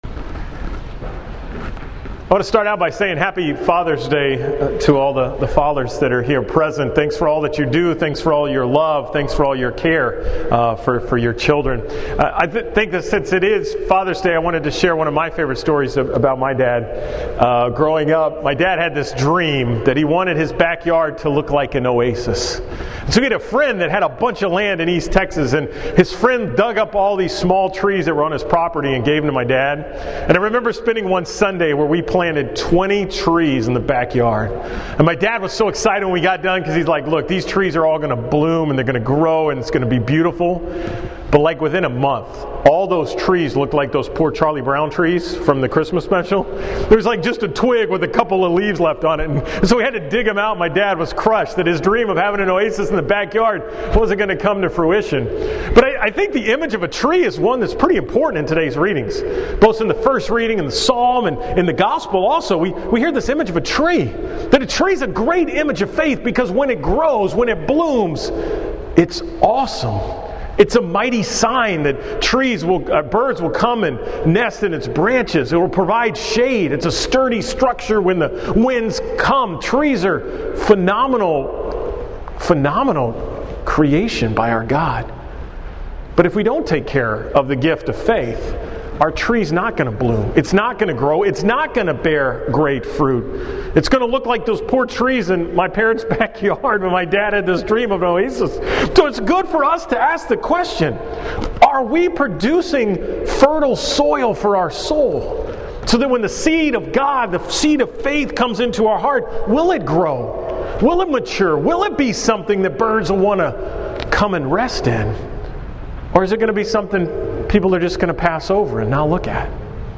From Mass at St. Frances Cabrini on Sunday, June 17, 2018 on the 11th Sunday in Ordinary Time